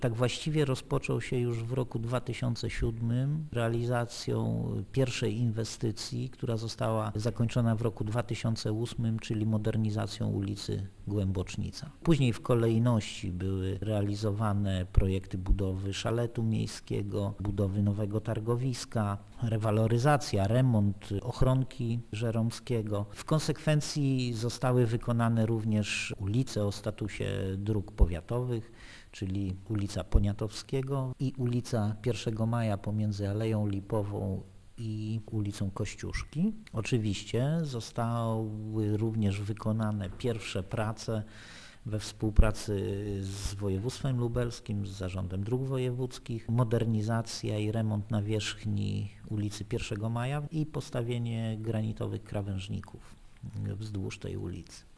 - Część inwestycji w ramach projektu została zrealizowana jeszcze przed jego złożeniem i została uznana jako tak zwany wkład własny gminy - przypomina zastępca burmistrza Nałęczowa Artur Rumiński: